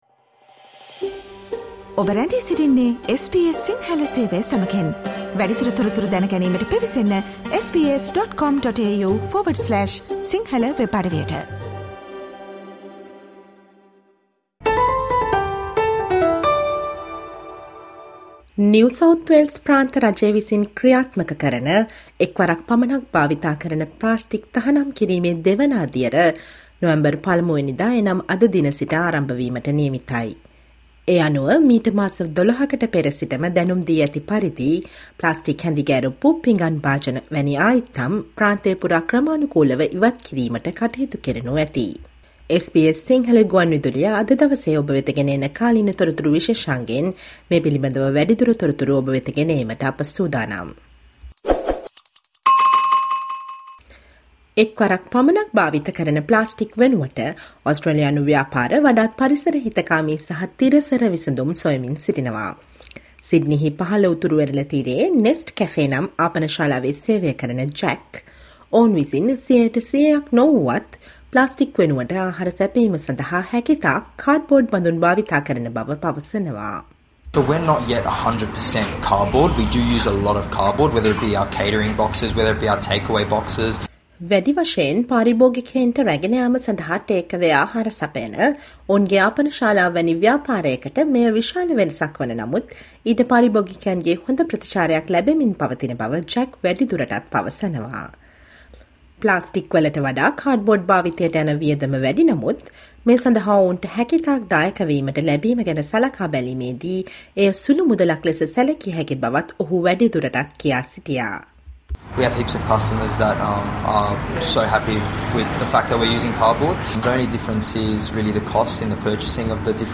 Listen to the SBS sinhala radio current affair feature on single-use plastic ban effects from today, breaching can results up to 55000 AUD.